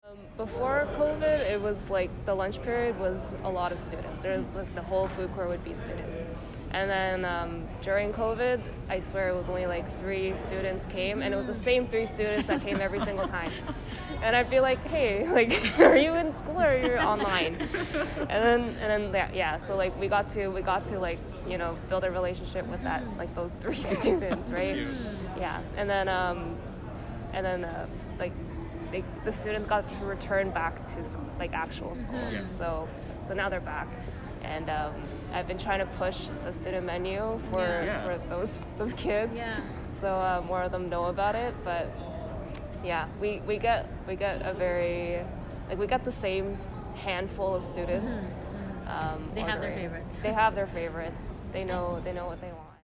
(intervieweur)